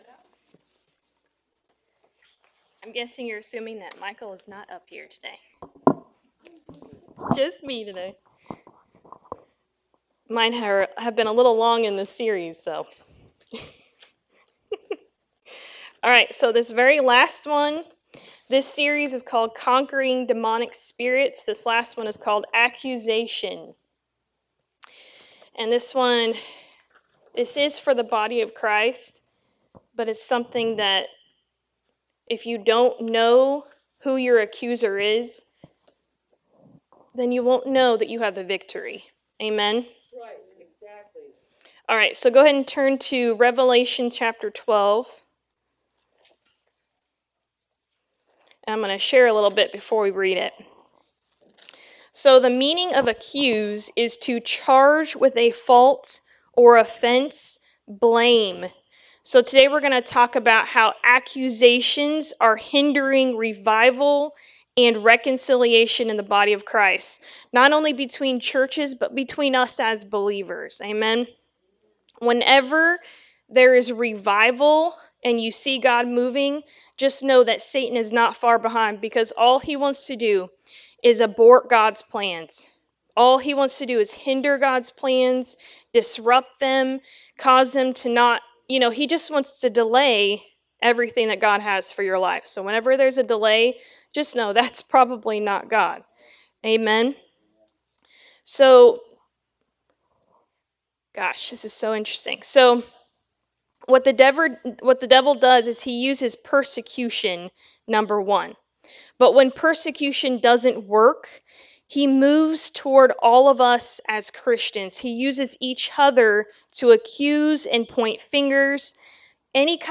Service Type: Sunday Morning Service
Sunday-Sermon-for-June-2-2024.mp3